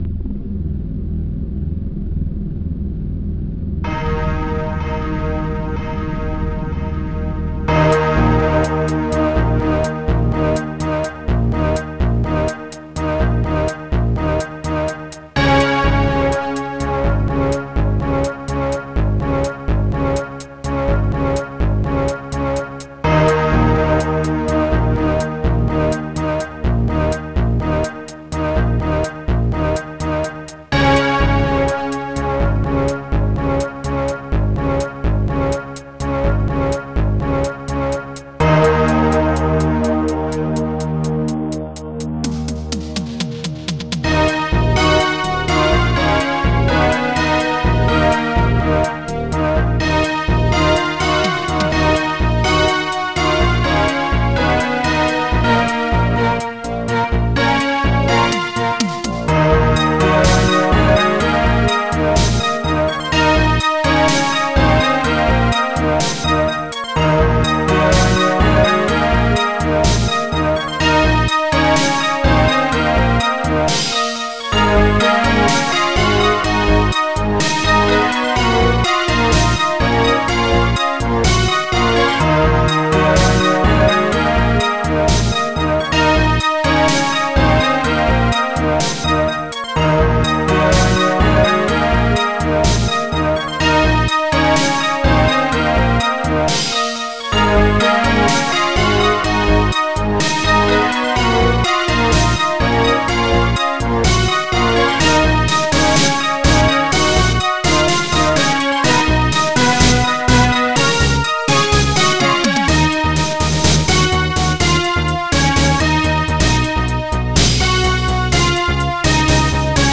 ST-29:Cymbal ST-29:ElecTom ST-29:Slaphi